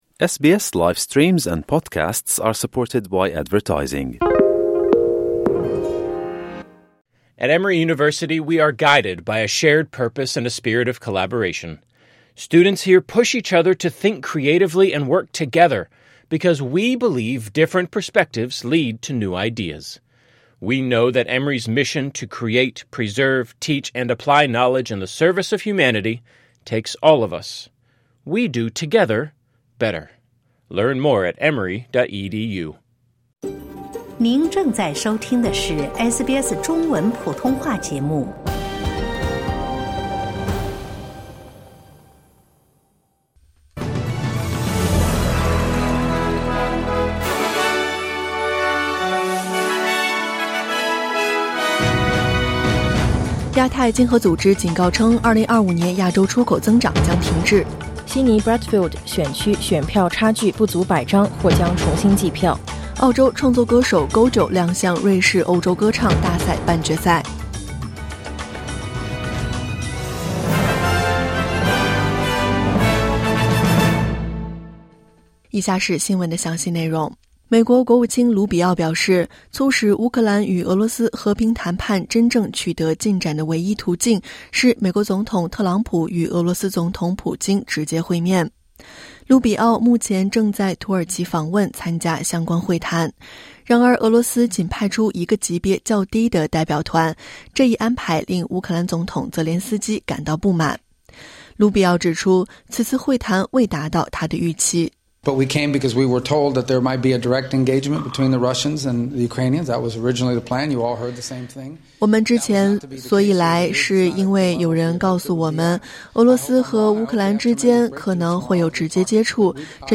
SBS早新闻（2025年5月16日）